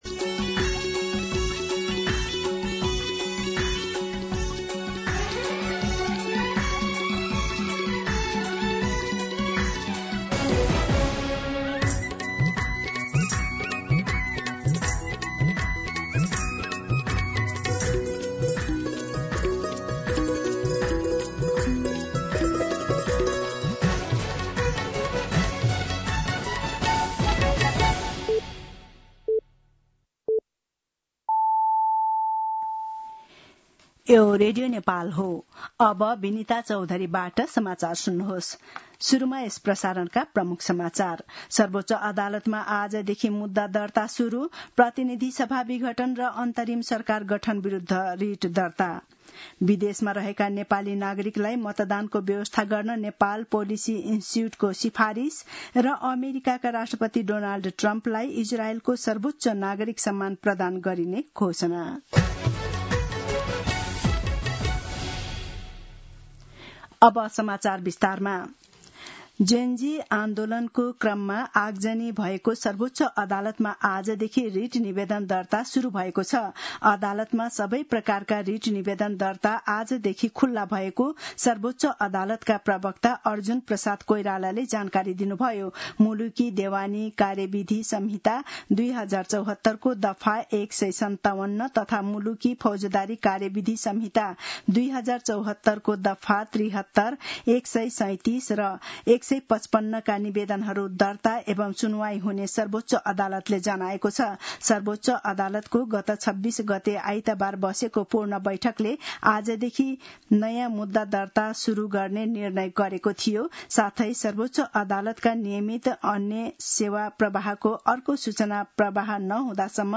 दिउँसो ३ बजेको नेपाली समाचार : २८ असोज , २०८२